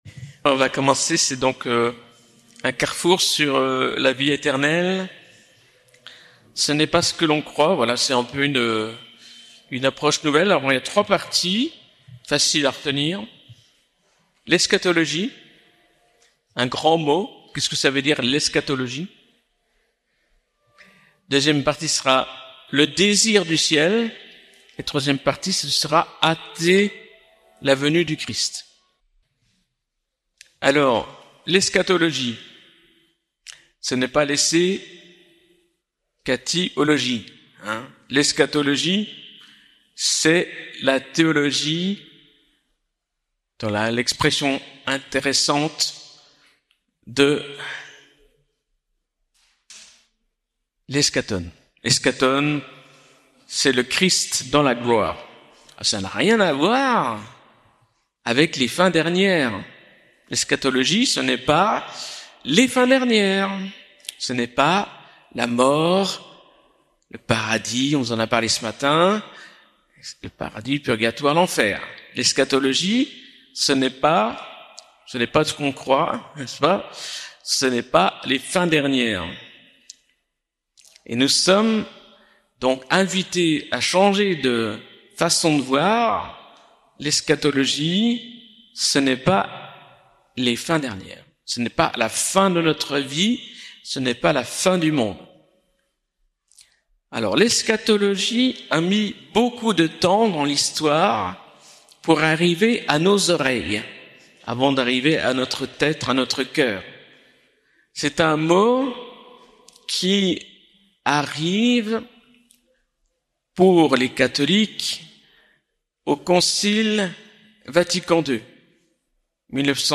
Lourdes - Session des béatitudes 2024